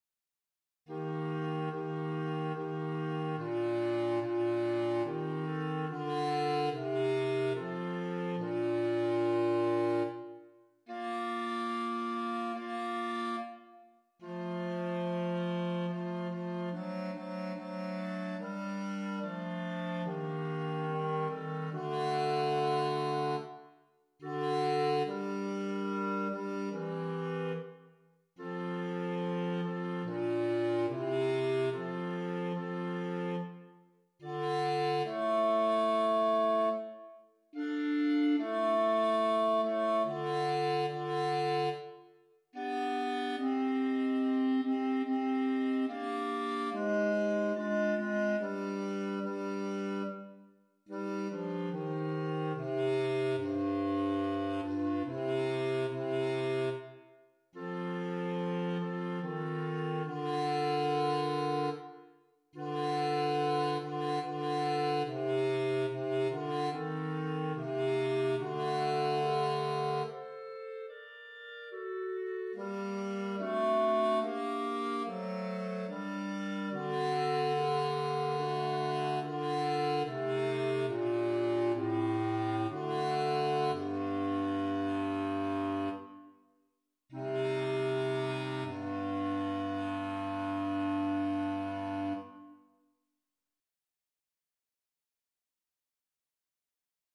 Thou_knowest_Lord_RR_Bass.mp3